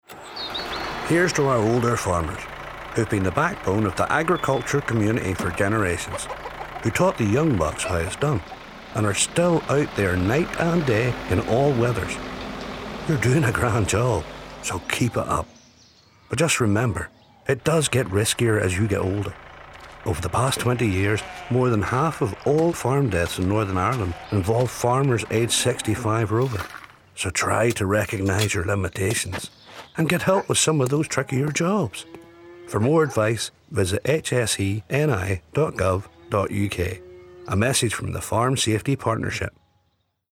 farming gets riskier with age - radio advert | Health and Safety Executive for Northern Ireland, controlling risk together